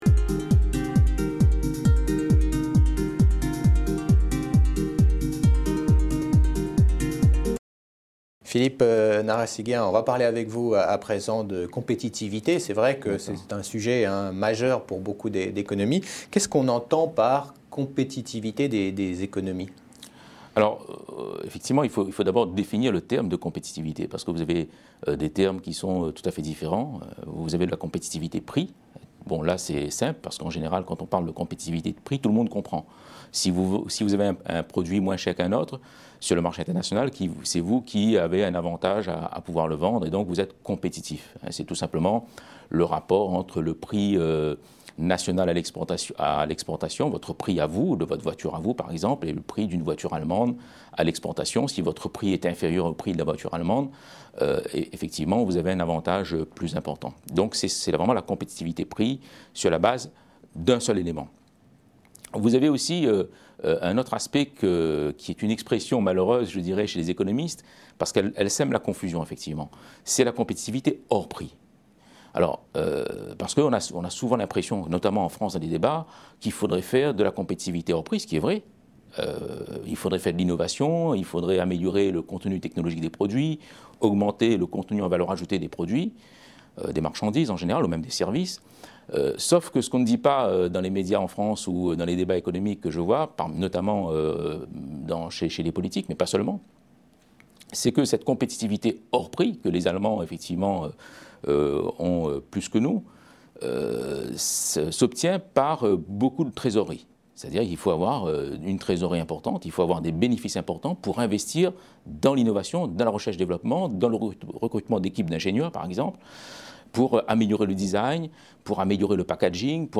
Economie et pédagogie : Interview